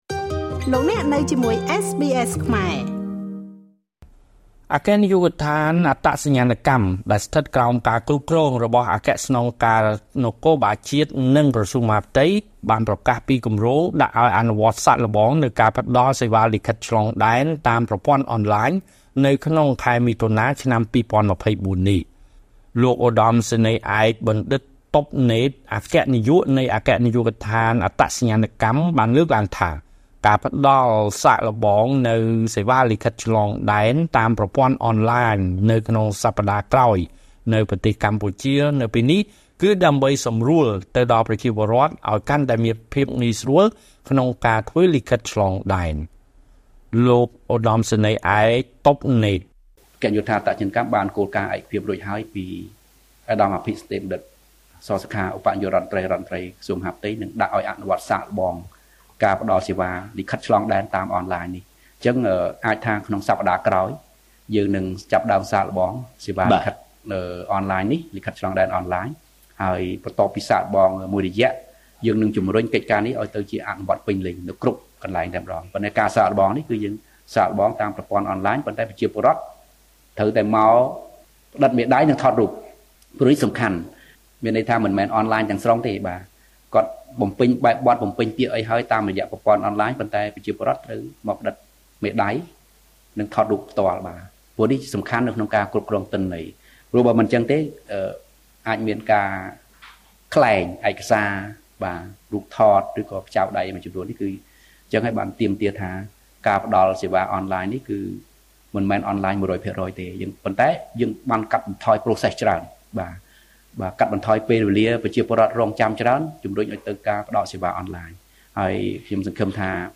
ការលើកឡើងរបស់លោកឧត្តមសេនីយ៍ឯក បណ្ឌិត តុប នេត អគ្គនាយក នៃអគ្គនាយកដ្ឋានអត្តសញ្ញាណកម្មខាងលើនេះ ត្រូវបានធ្វើឡើងក្នុងបទសម្ភាសន៍ នៅស្ថានីយទូរទស្សន៍មហាផ្ទៃ MOI-TV ស្តីពី វឌ្ឍនភាពការងាររបស់អគ្គនាយកដ្ឋានអត្តសញ្ញាណកម្ម ក្នុងឆមាសទី១ នៅឆ្នាំ២០២៤ ចេញផ្សាយនៅថ្ងៃទី១៣ ខែមិថុនា ឆ្នាំ២០២៤។